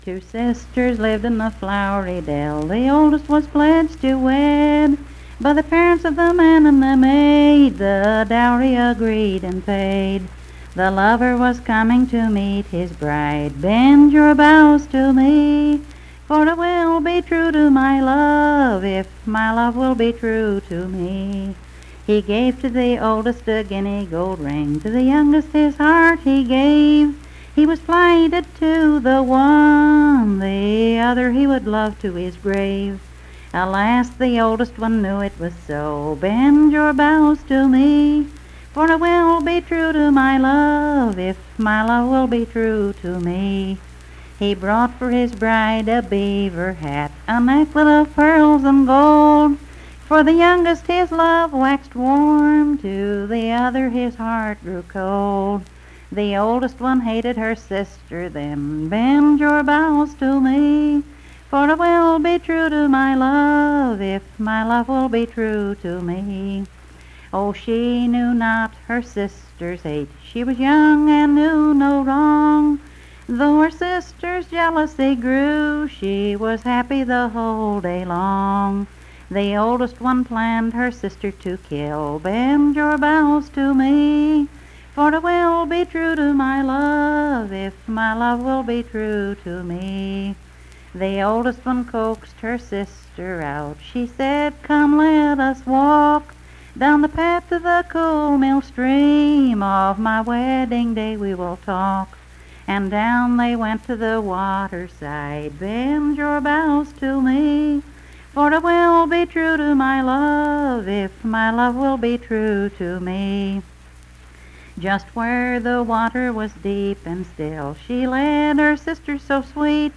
Unaccompanied vocal music
Verse-refrain 21(4w/R).
Performed in Coalfax, Marion County, WV.
Voice (sung)